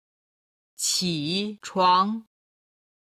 起床　(qǐ chuáng)　起床